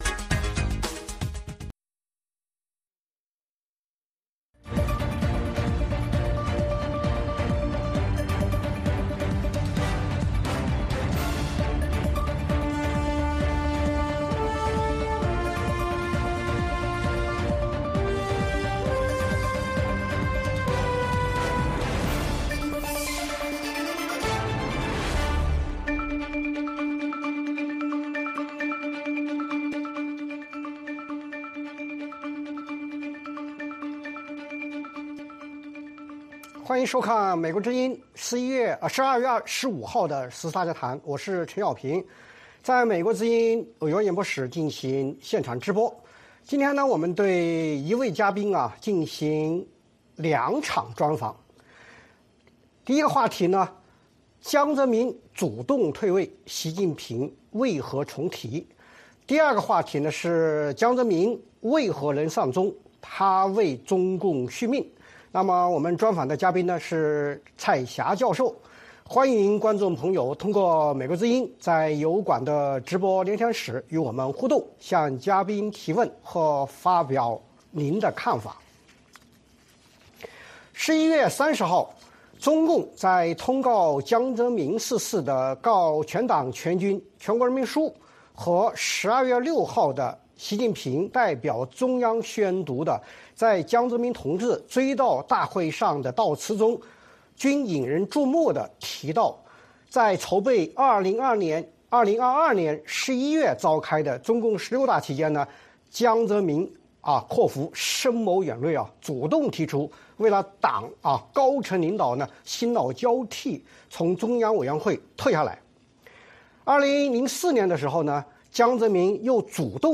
VOA卫视-时事大家谈：专访蔡霞：江泽民主动退位？